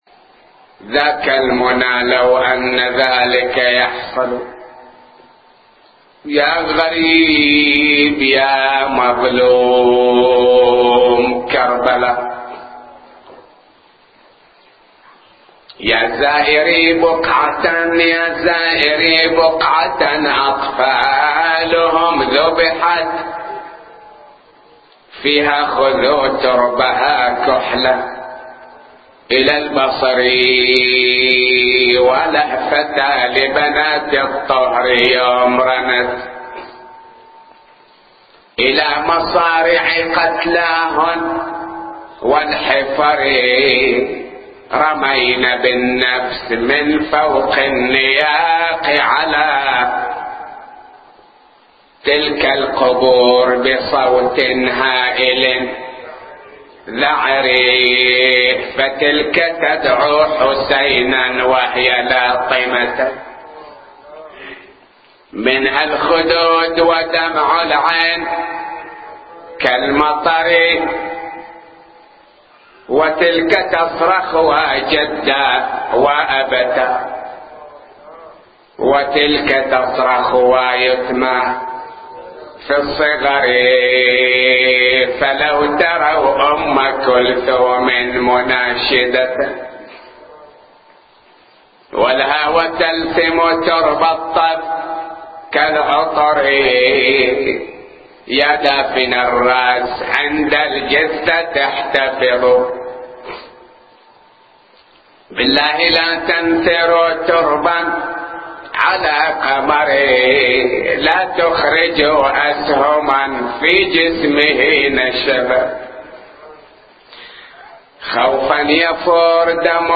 نواعي وأبيات حسينية – 3